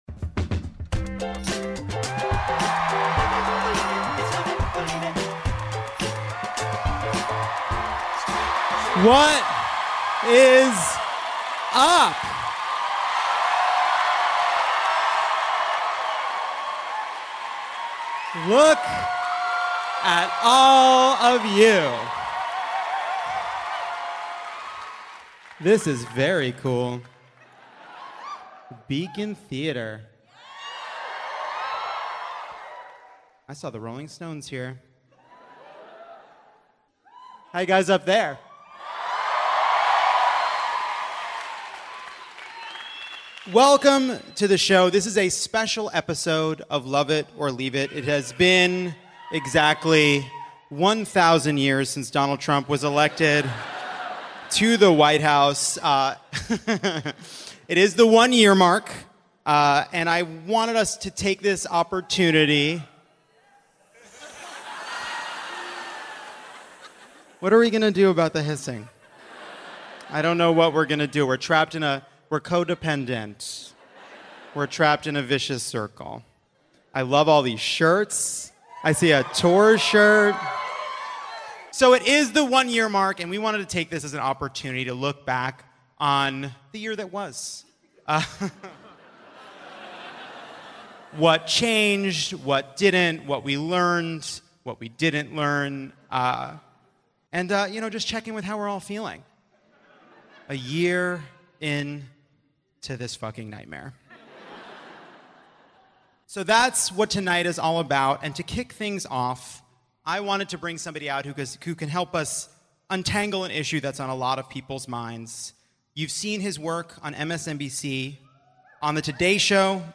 Ronan Farrow, Katy Tur, Alex Wagner, and Michelle Wolf join Jon at the Beacon Theatre in New York City for a special episode of Lovett or Leave It marking one year since Election Day 2016. Plus, a Paul Ryan-themed quiz, the meaning of the Virginia victories, and rants about true crime podcasts because what is going on there?